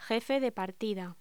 Locución: Jefe de partida